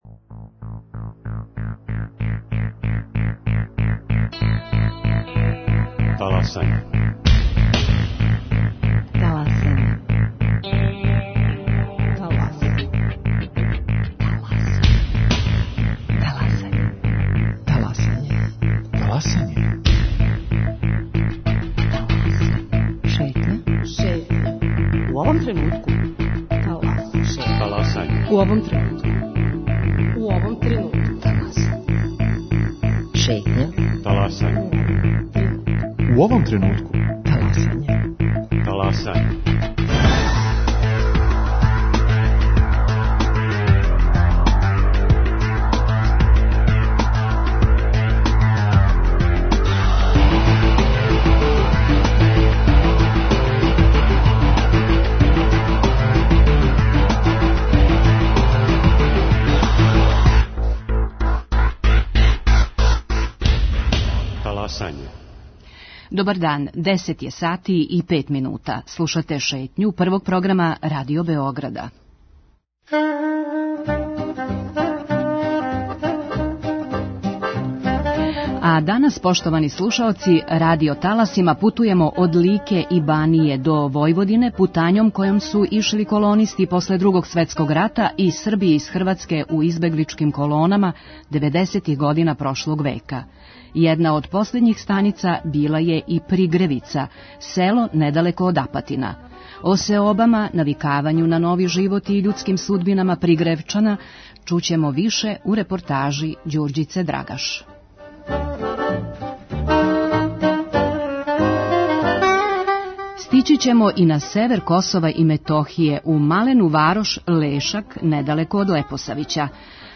Радио Београд Један, 10:05.